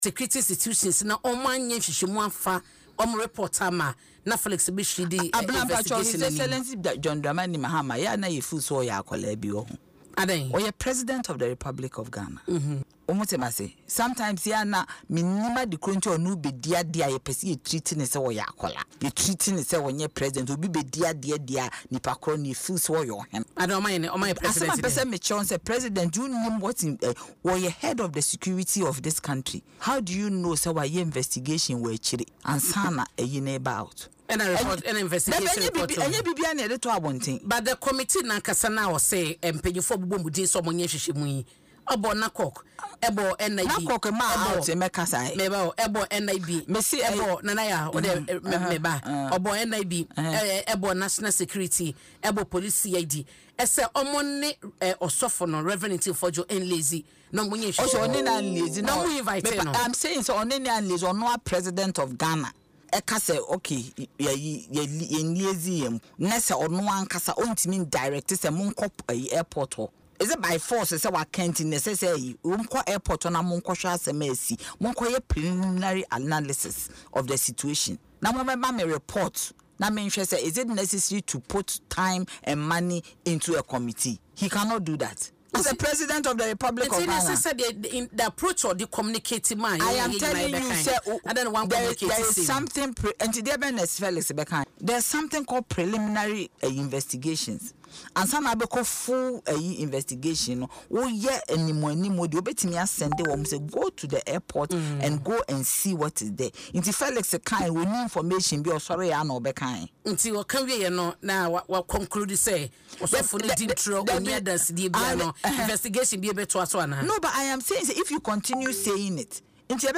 In an interview on Adom FM’s Dwaso Nsem, Madam Jantuah said as the head of security in the country, Mahama had likely already conducted investigations before any public discussions on the matter.